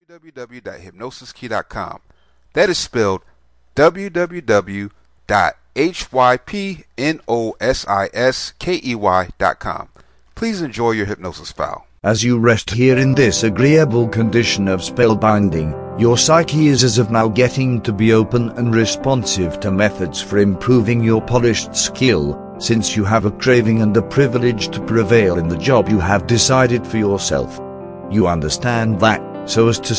Professionalism Self Hypnosis Mp3
Welcome to prosperity Self Hypnosis Mp3, this is a powerful self hypnosis. This script helps you gain prosperity in your life. This mp3 helps you meditate and feel good in your mind body and spirit.